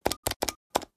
This Category features a great Collection of high quality Calculator Sound Effects.
Sharp-calculator-button-press.mp3